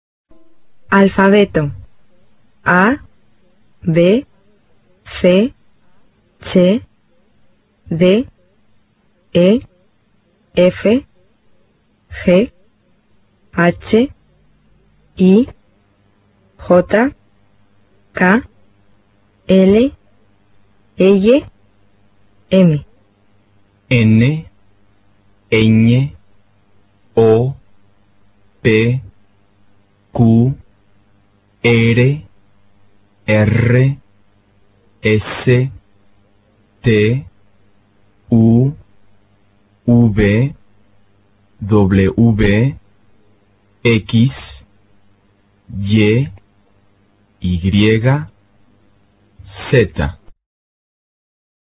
西班牙语发音入门：字母表&学习目录